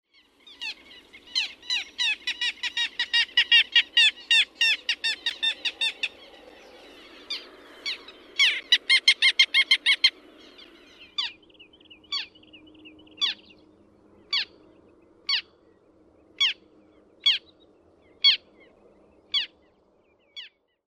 Pikkulokin ääni on nenäsointinen, hieman naakkamainen